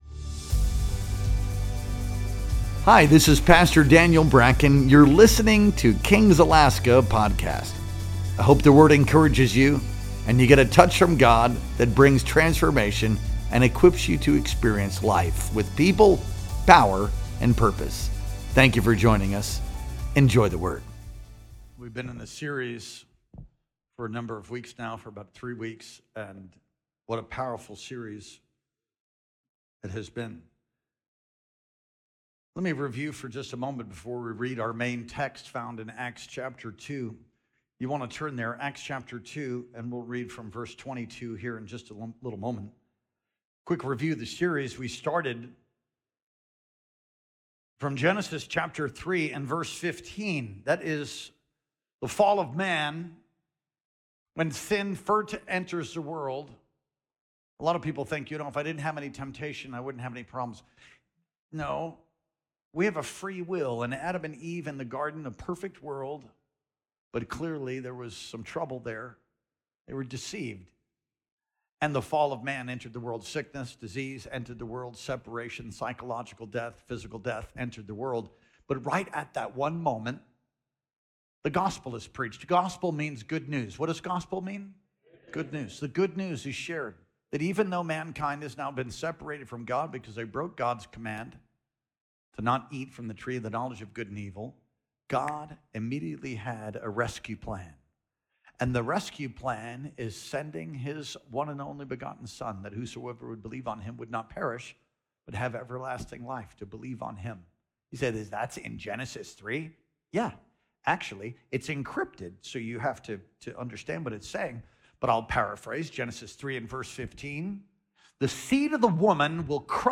Our Easter Sunday Worship Experience streamed live on April 20th, 2025.